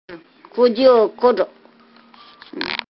山口方言ライブラリ